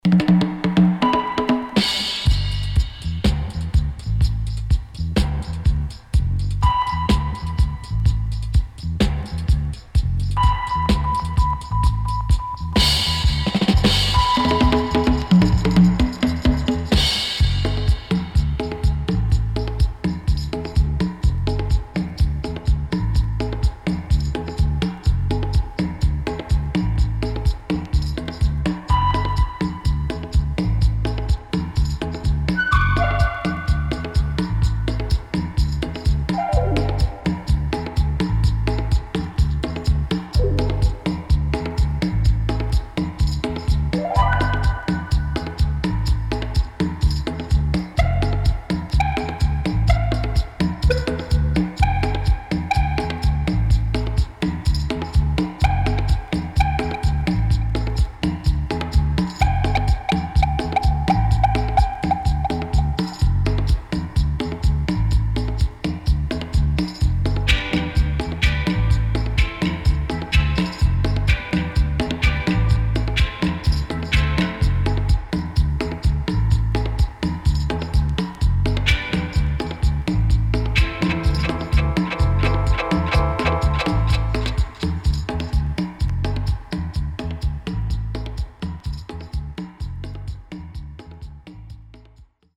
テンポの良いCongo Drumの音色がとても気持ちよい名曲.後半Deejay接続 & Dubwise
SIDE A:少しチリノイズ入りますが良好です。